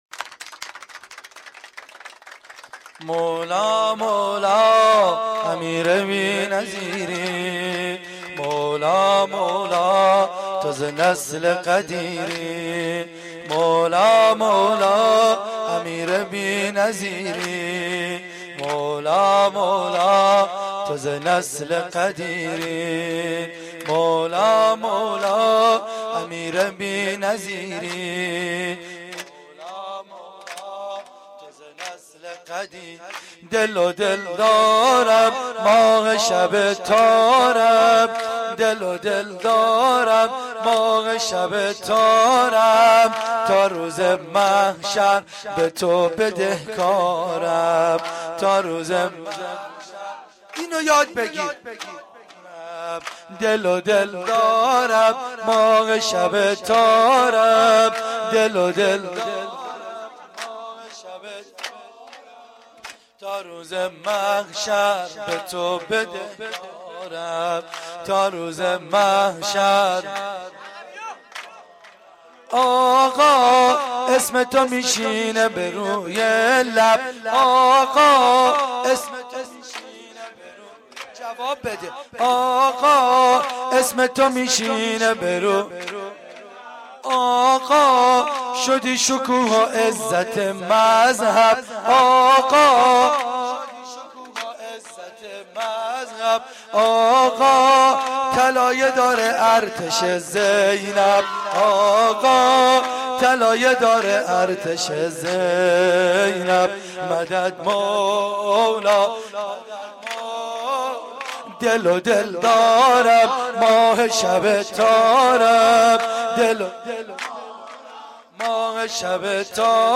روضه العباس
sorood.mp3